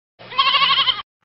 羊 | 健康成长
yangjiao.mp3